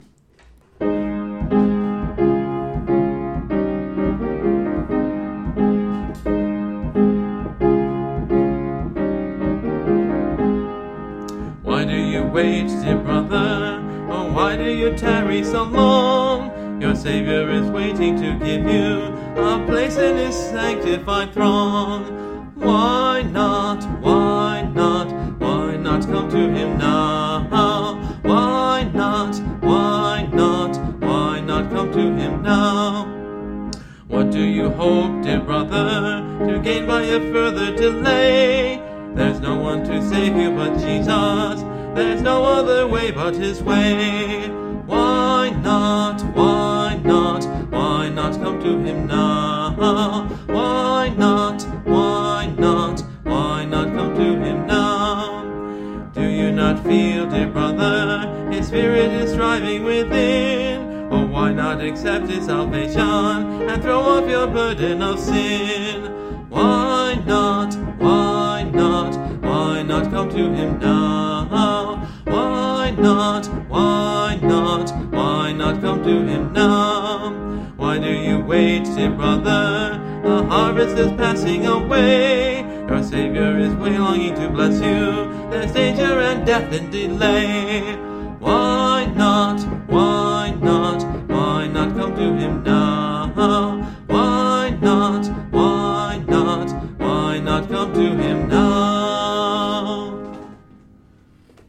(Part of a series singing through the hymnbook I grew up with: Great Hymns of the Faith)
This song is a simple yet tricky one to play/sing. The melody makes sense, but both my fingers and voice wanted to change it in places